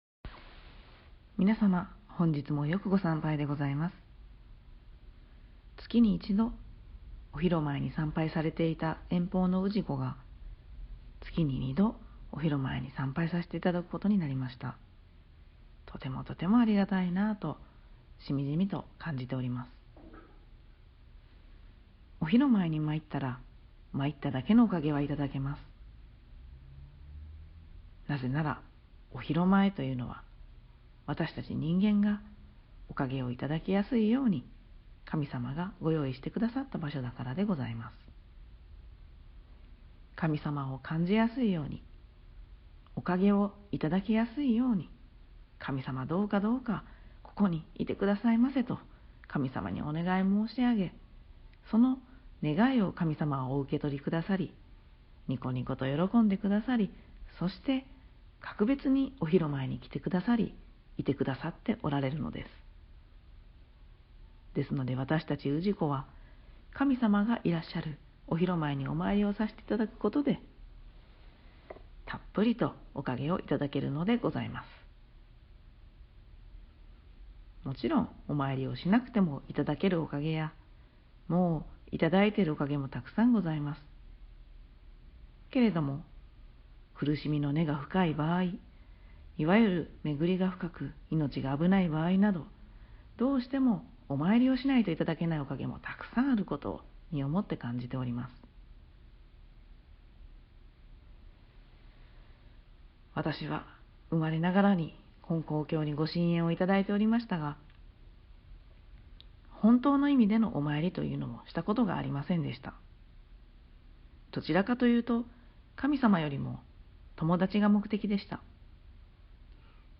【教話】 第十二集「神様と繋がる」(MP3)